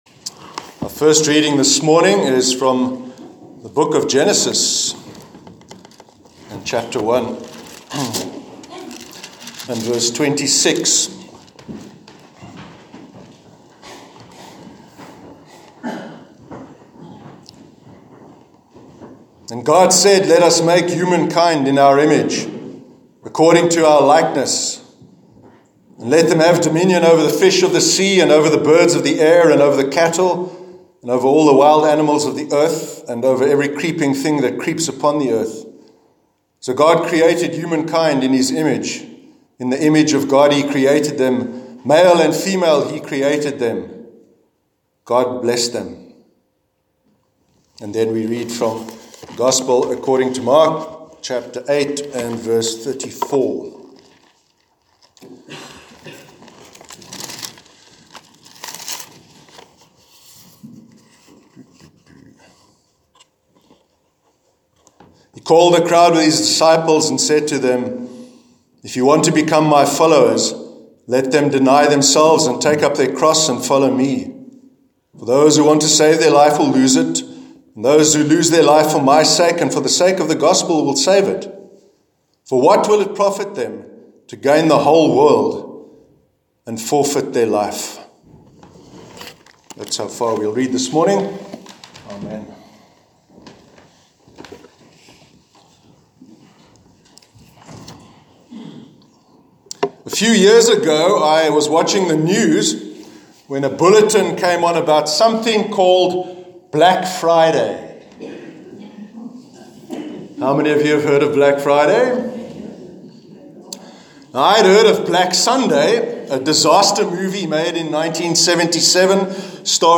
Sermon on Transcending Self-Absorption- 19th November 2018